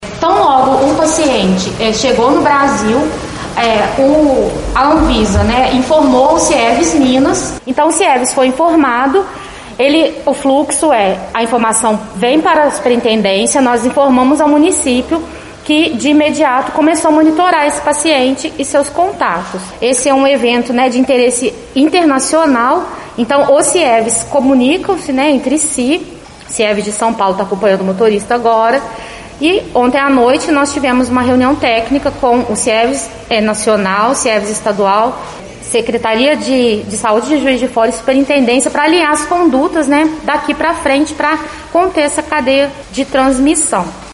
Em coletiva de imprensa na tarde desta sexta-feira, 28, O caso da pessoa internada, em Juiz de Fora, com a cepa indiana do novo coronavírus foi explicado pela Secretaria Municipal de Saúde e pela Superintendência Regional de Saúde de Juiz de Fora (SRS-JF).
chegou_Coletiva-cepa-indiana-JF_Sup.-Int.-Reg.-Saude-Cimara-Vieira.mp3